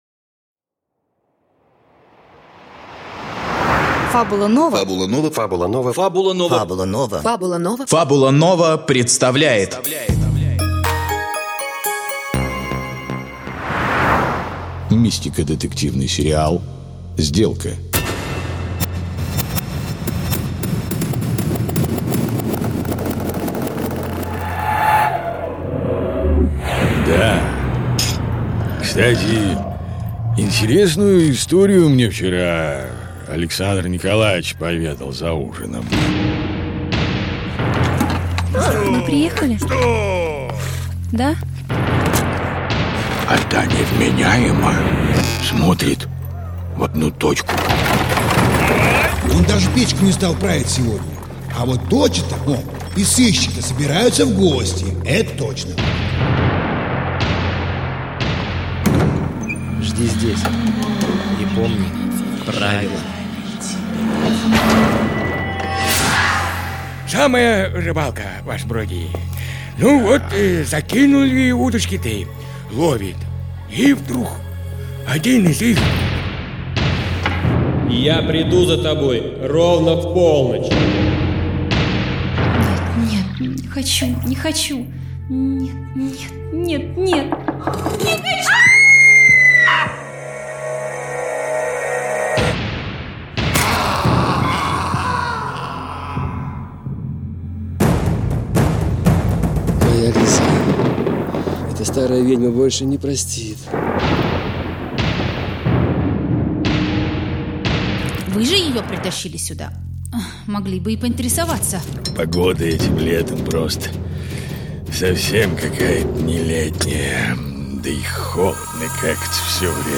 Аудиокнига Сделка. Тамбико (1-й сезон, серии 01-08) | Библиотека аудиокниг